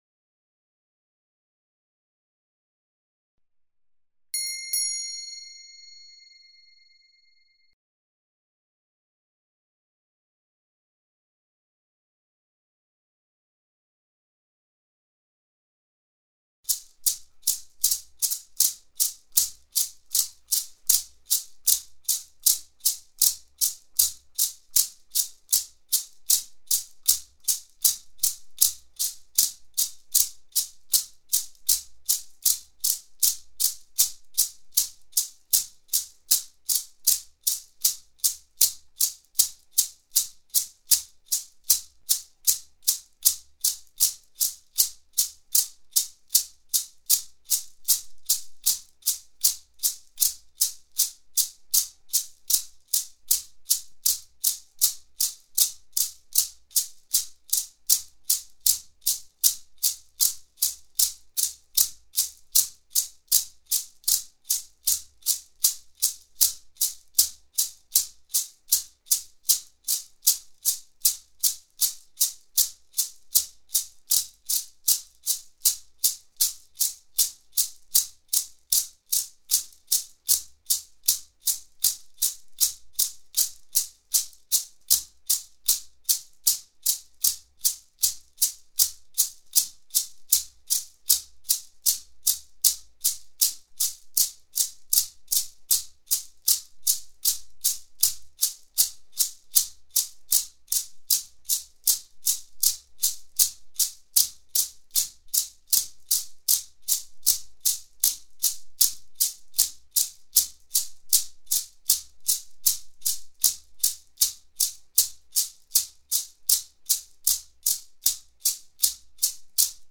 Click here to download the Asha Meditation with rattle
Asha-Meditation-Rattling-30-minutes.mp3